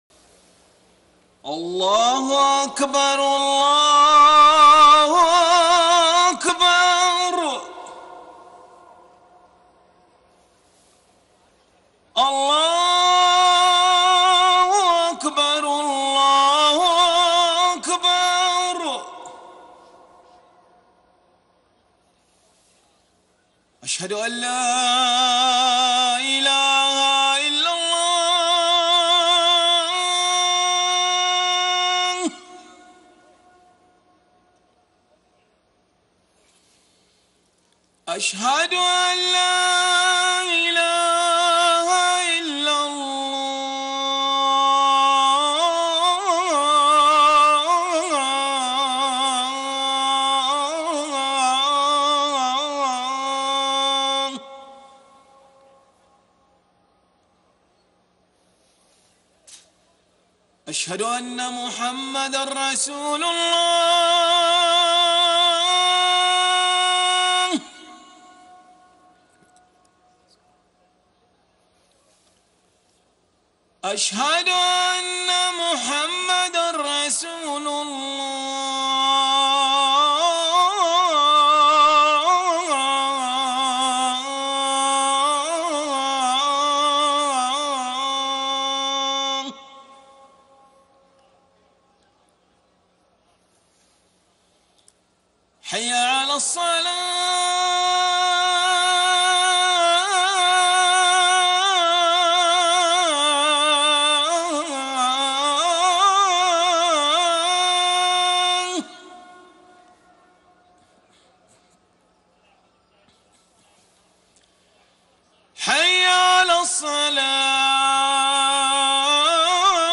صلاة المغرب 22 شوال 1433هـ من سورة آل عمران 26-32 > 1433 هـ > الفروض - تلاوات ماهر المعيقلي